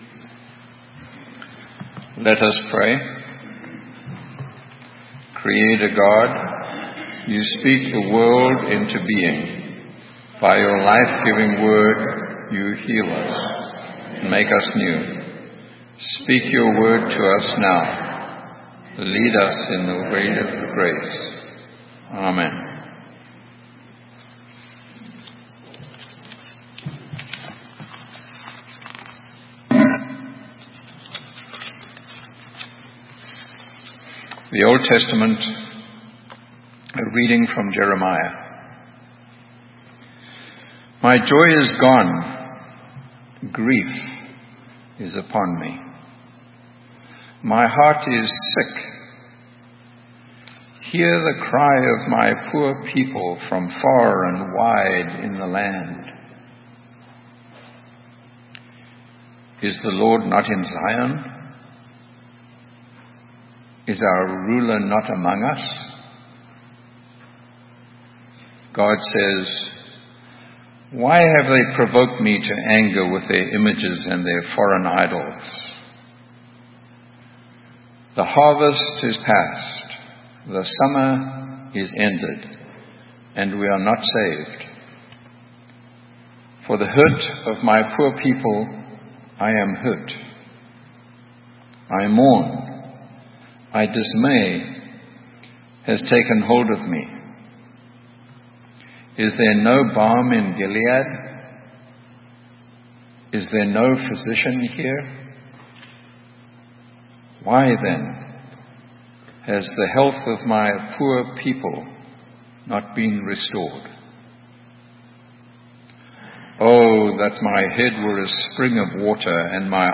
Sermon: You don't owe - Saint Matthew's UMC Acton, MA